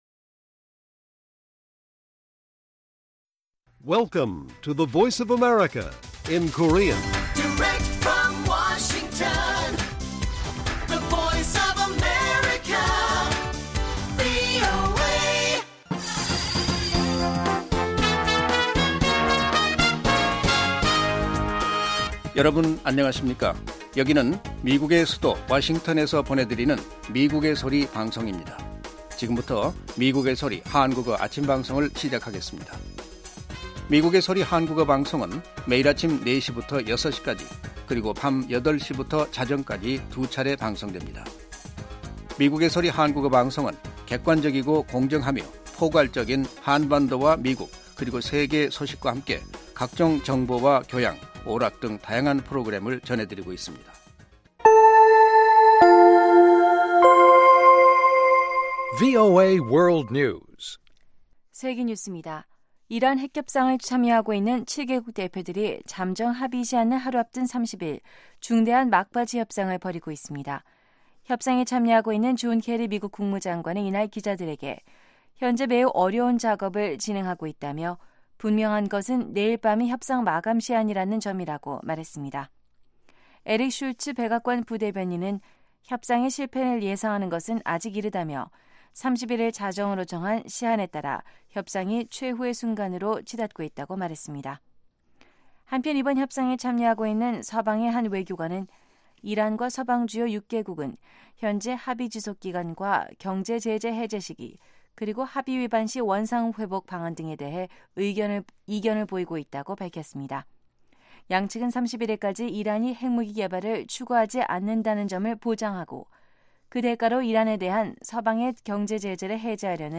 VOA 한국어 방송의 아침 뉴스 프로그램 입니다. 한반도 시간 매일 오전 4시부터 5시까지 방송됩니다.